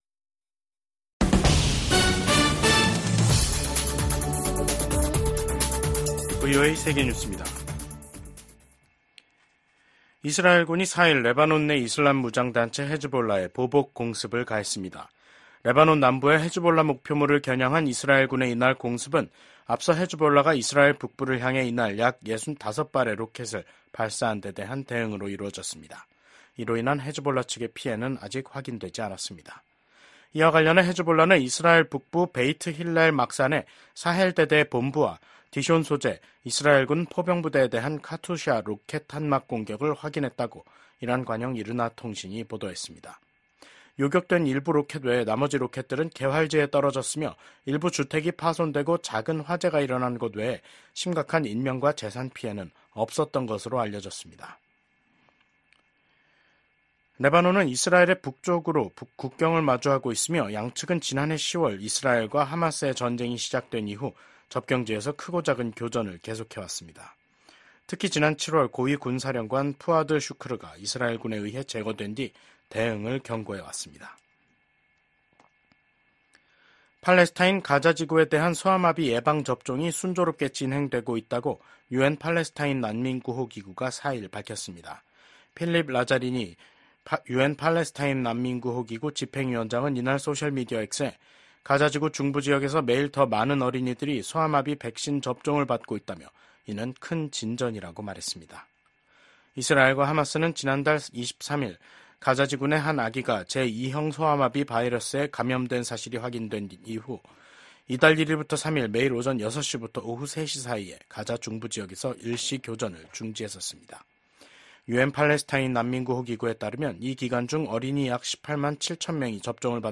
VOA 한국어 간판 뉴스 프로그램 '뉴스 투데이', 2024년 9월 4일 3부 방송입니다. 미국 국무부가 한국 정부의 대북 라디오 방송 지원 방침을 지지한다는 입장을 밝혔습니다. 기시다 후미오 일본 총리가 퇴임을 앞두고 한국을 방문해 윤석열 대통령과 회담을 합니다. 북한 열병식 훈련장 인근에 버스로 추정되는 차량 수십 대가 집결했습니다.